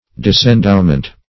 Search Result for " disendowment" : The Collaborative International Dictionary of English v.0.48: Disendowment \Dis`en*dow"ment\, n. The act of depriving of an endowment or endowments.